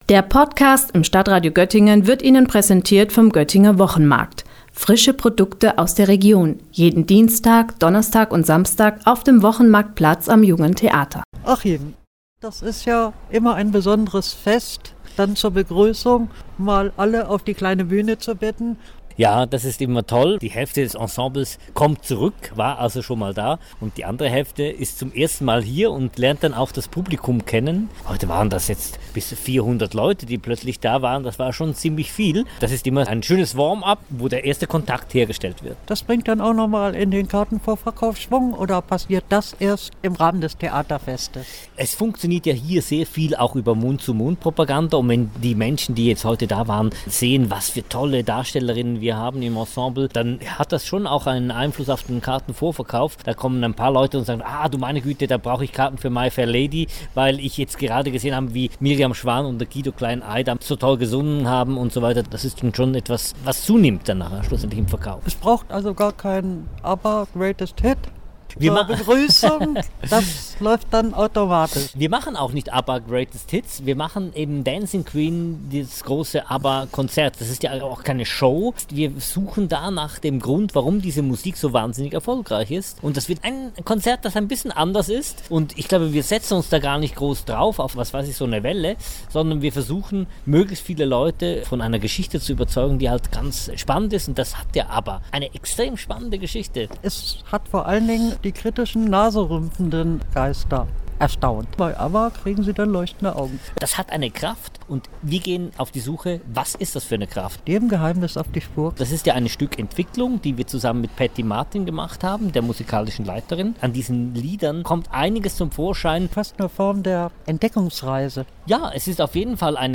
Beiträge > Begrüßungsfest bei den Gandersheimer Domfestspielen – Gespräch